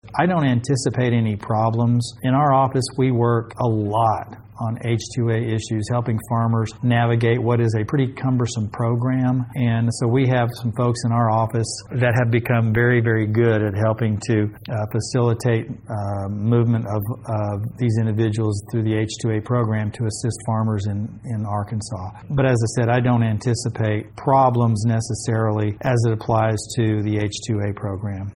Again that was Congressman Rick Crawford who represents the First District of Arkansas.